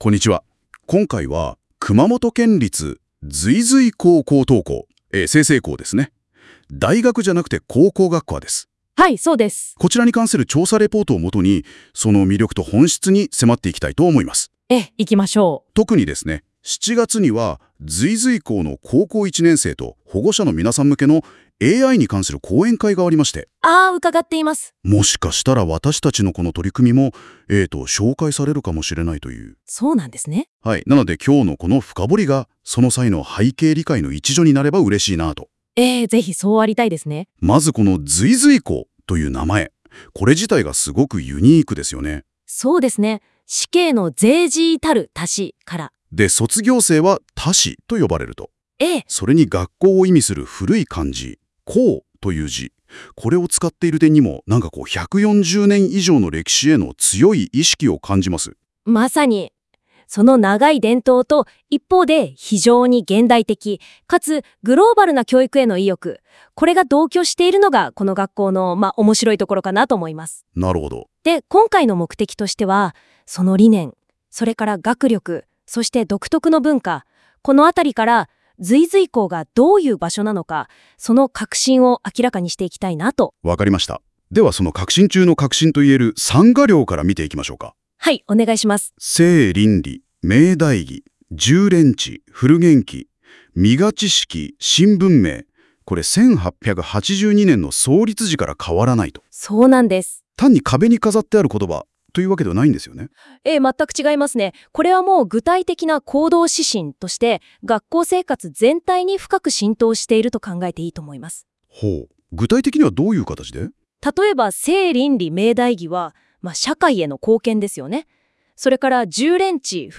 AIによる済々黌のPodCast